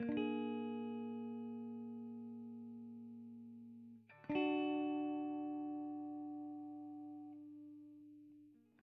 Dark Smooth Loop
This is a loop that I created with my guitar playing 2 chords...
Pause guitar.ogg